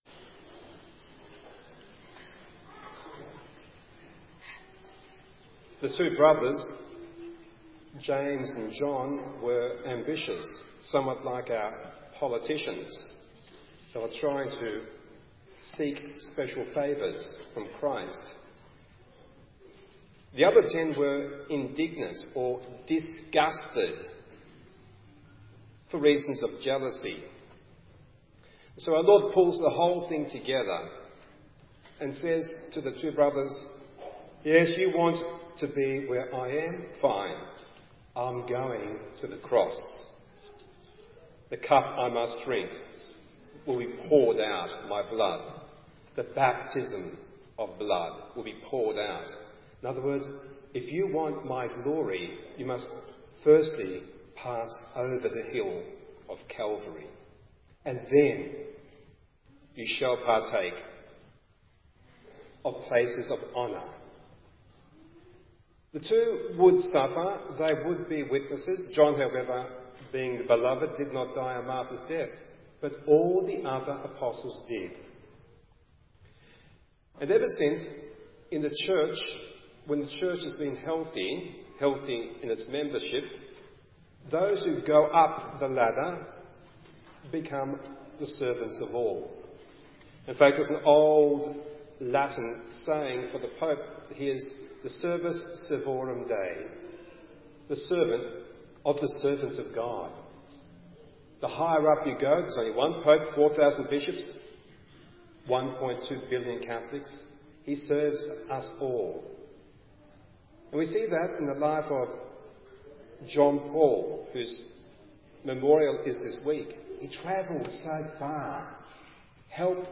Recorded Homily, Sunday 18th October 2015: